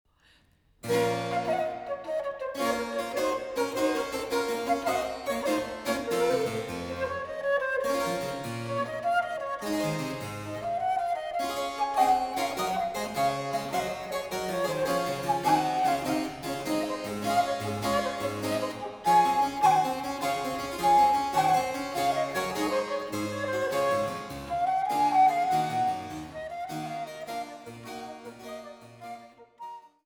Pompeusement